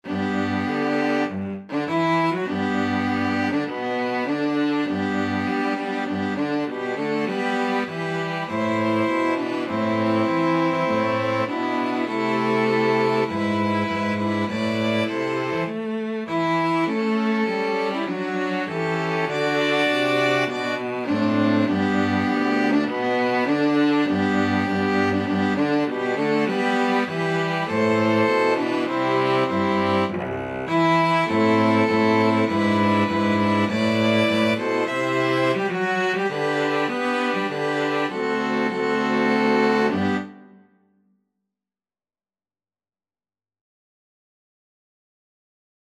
4/4 (View more 4/4 Music)
Classical (View more Classical String Quartet Music)